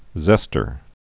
(zĕstər)